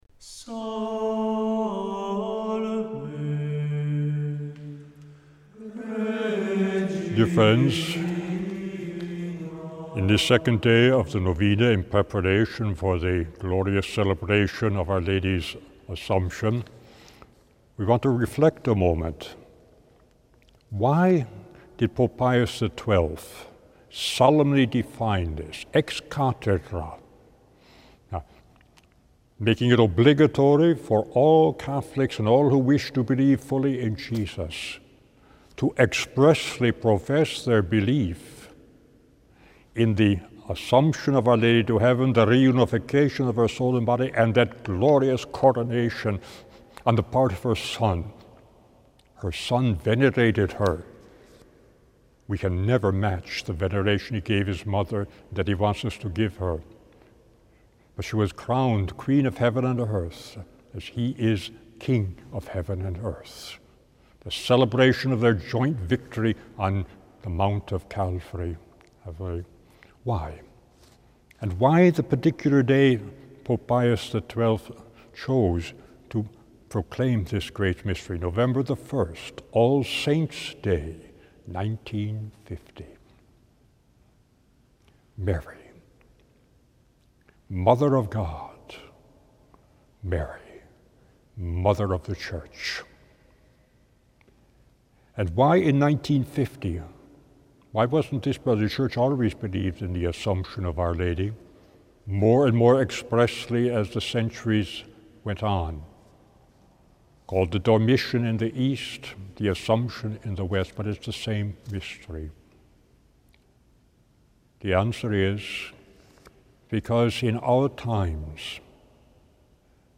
Each day, from the 6th up to the 14th, he gives a short reflection on Our Lady followed by a prayer.